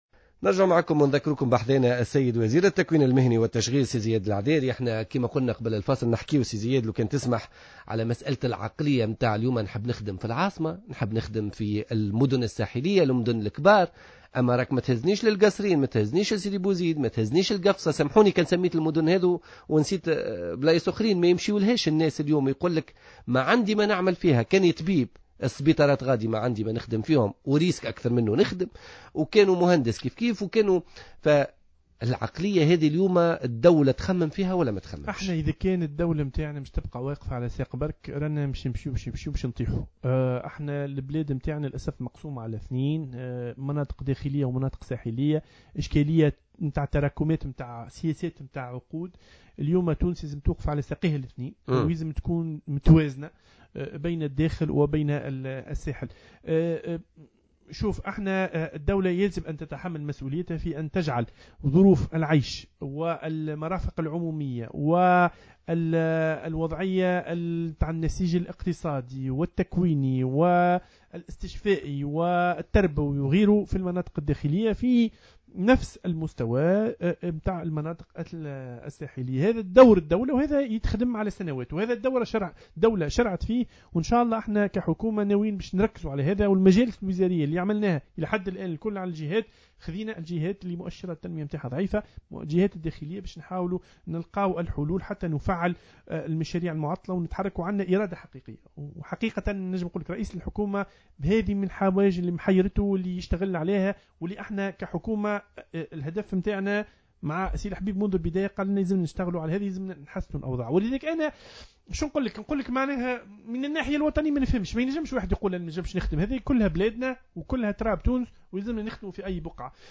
أكد وزير التشغيل والتكوين المهني زياد العذاري اليوم الاثنين 11 ماي 2015 في برنامج "بوليتيكا" على "جوهرة أف أم" أنه بتوقيع اتفاقيات مع شركات دولية في إطار مشروع "تونس الذكية" فإنه سيتم توفير نحو 50 ألف فرصة عمل على امتداد خمس سنوات مقبلة.